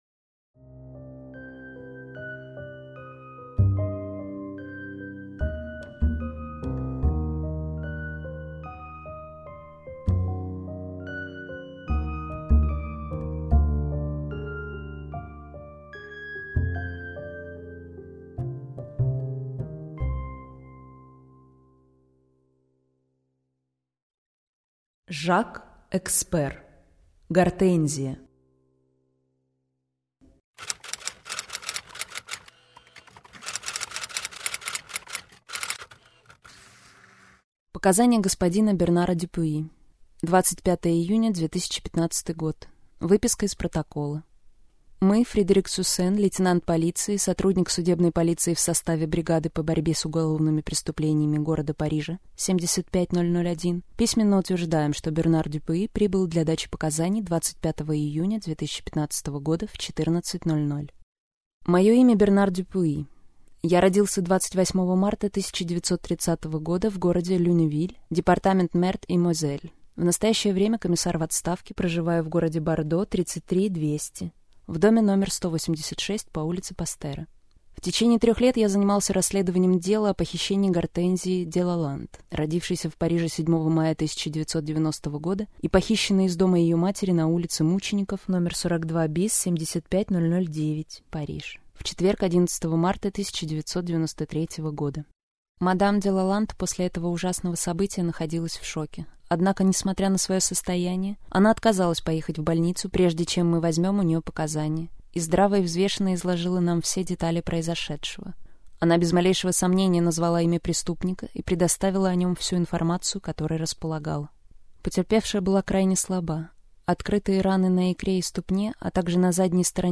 Прослушать фрагмент аудиокниги Гортензия Жак Экспер Произведений: 1 Скачать бесплатно книгу Скачать в MP3 Вы скачиваете фрагмент книги, предоставленный издательством